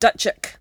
[dUTCH-hk]